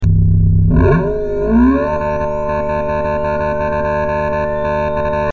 First i was experimenting with 32band-EQ and Compressor to get a real muffler sound effect from the engine sound.
here u can see an example EQ to make engine sound like muffler-like
Muffler_Raceabout.mp3 - 124.7 KB - 582 views